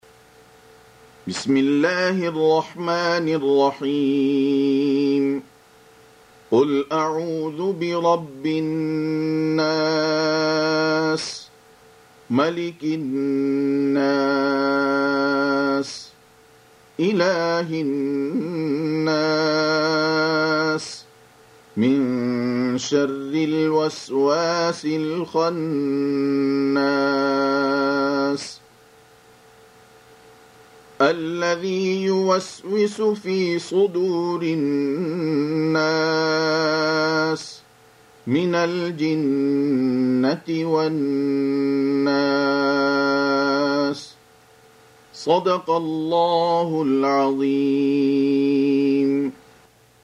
Audio Quran Tarteel Recitation
Surah Repeating تكرار السورة Download Surah حمّل السورة Reciting Murattalah Audio for 114. Surah An-N�s سورة النّاس N.B *Surah Includes Al-Basmalah Reciters Sequents تتابع التلاوات Reciters Repeats تكرار التلاوات